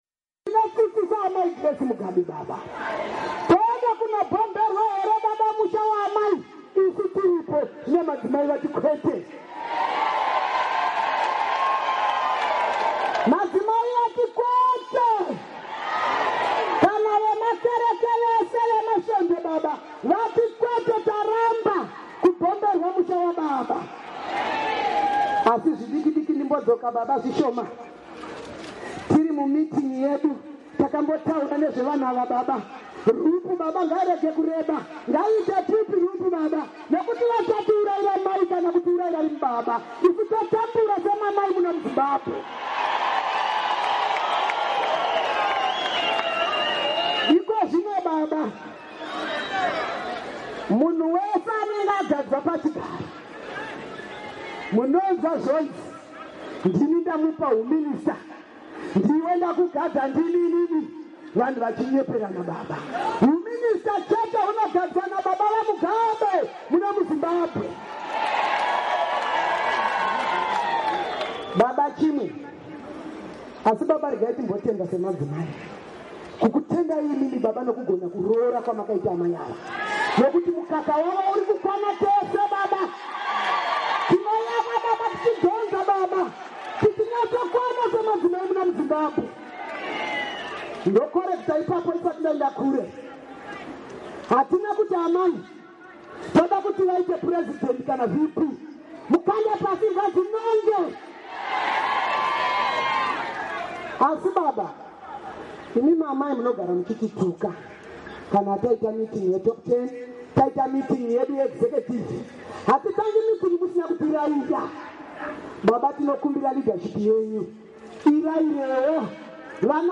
Mashoko aAmai Sarah Mahoka